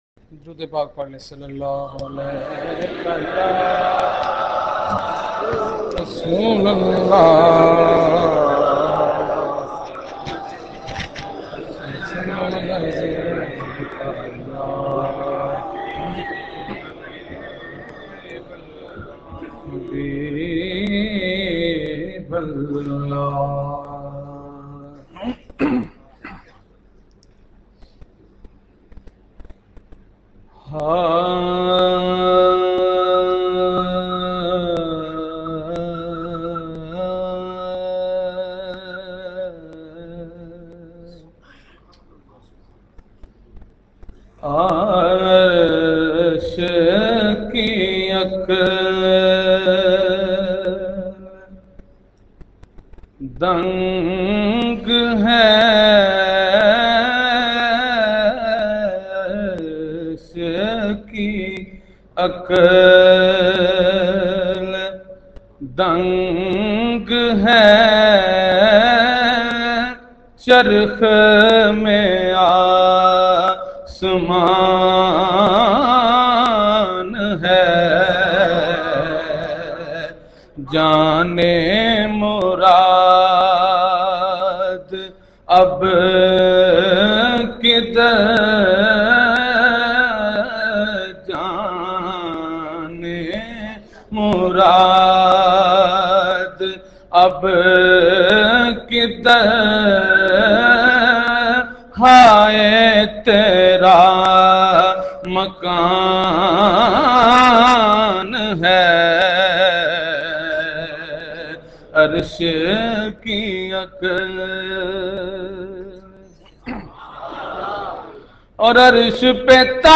Mehfil e Naat o Bayan held on 07 February 2010 at
Noori Masjid, Abu Dhabi, UAE on the occassion of
Naat e Rasool
naat3.mp3